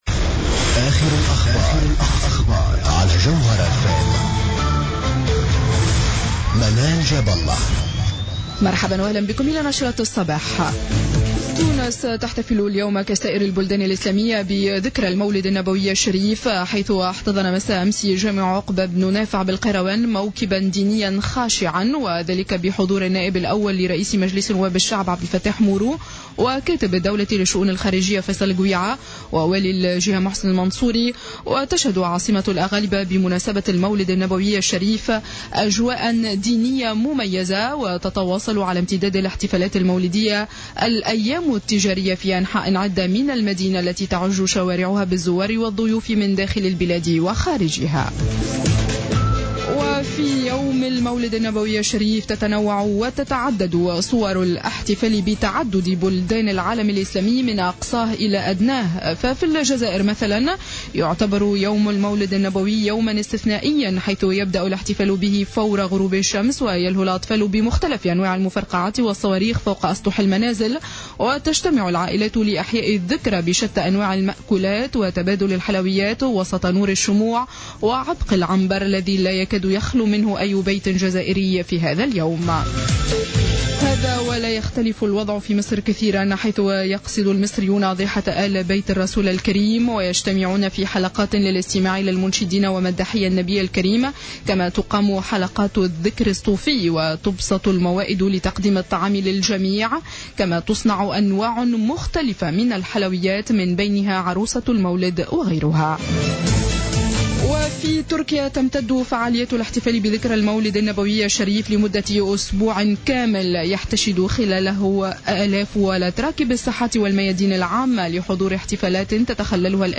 نشرة اخبار السابعة صباحا ليوم السبت 03-01-15